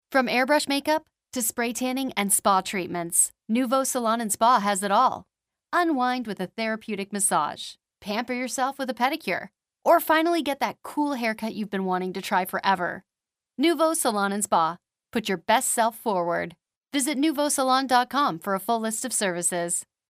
Female
My voice is best described as perky, enthusiastic, and bubbly. I have a "cool mom" kind of sound, and love any scripts that call for a bit of sarcasm.
Phone Greetings / On Hold
Studio Quality Sample
Words that describe my voice are friendly, announcer, upbeat.